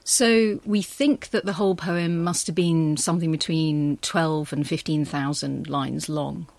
It’s been stripped down to a simple /ə/. The /h/ and the /v/ are elided.